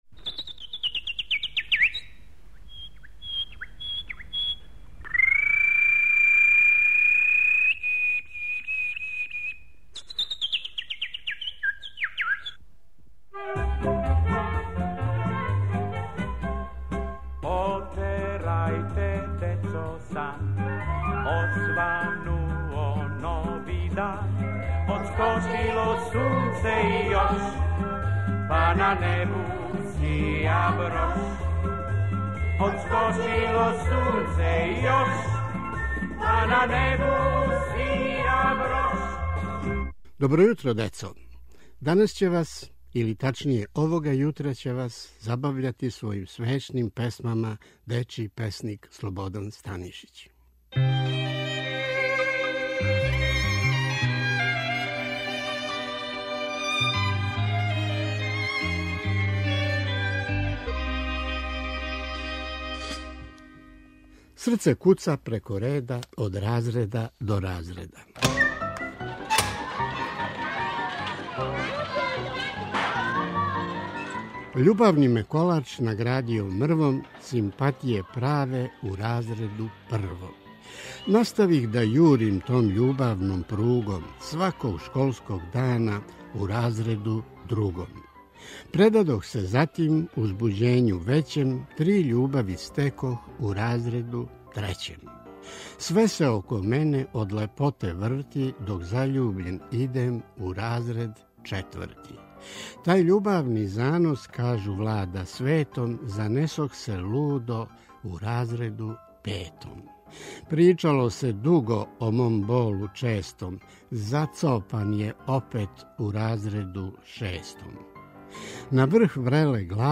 Поезија